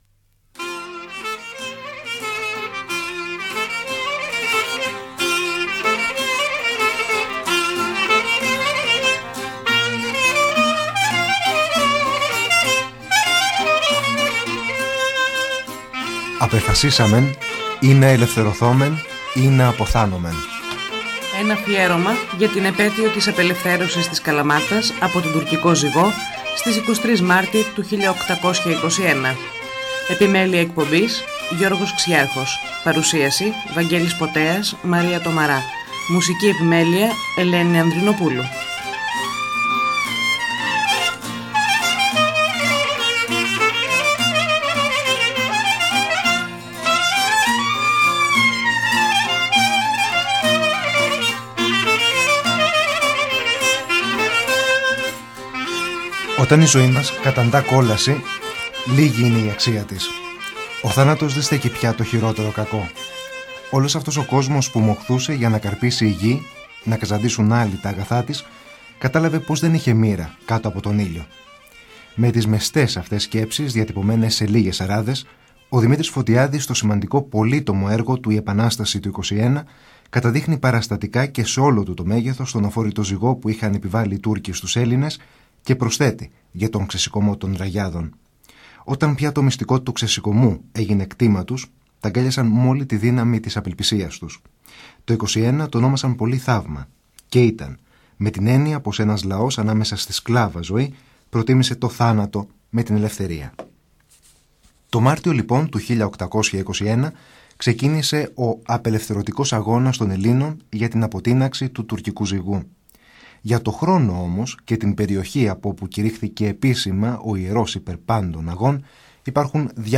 Από το Αρχείο της ΕΡΤ Καλαμάτας.